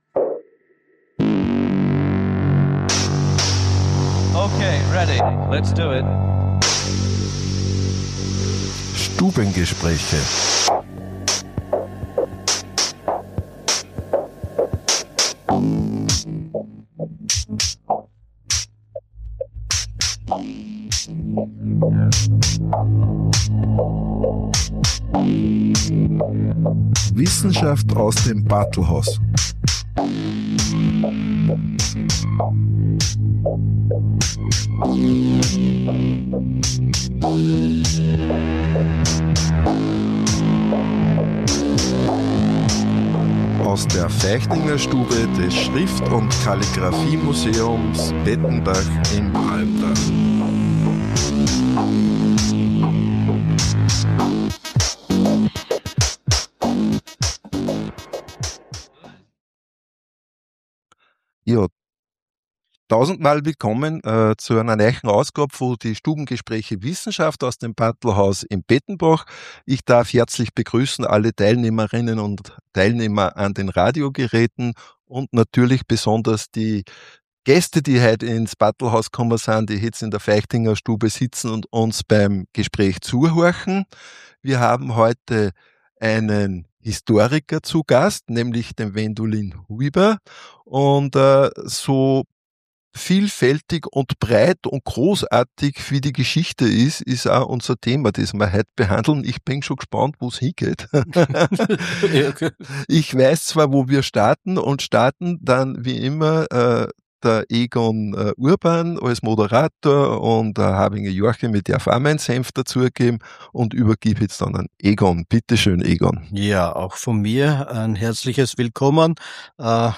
Stubengespräch Wissenschaft
Im Bartlhaus z’Pettenbach, Feichtingerstube
Historiker